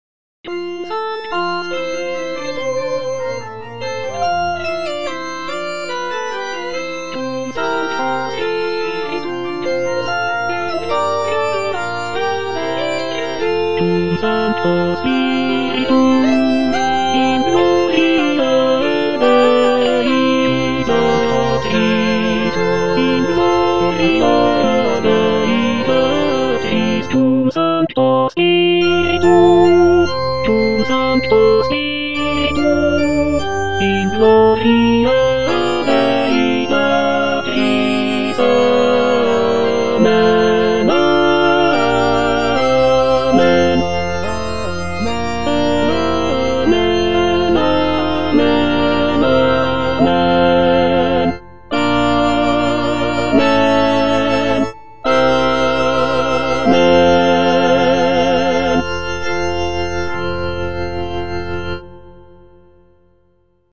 T. DUBOIS - MESSE IN F Cum sancto spiritu - Tenor (Emphasised voice and other voices) Ads stop: auto-stop
"Messe in F" is a choral work composed by Théodore Dubois in the late 19th century. It is a setting of the traditional Catholic Mass text in the key of F major. The piece is known for its lush harmonies, intricate counterpoint, and lyrical melodies.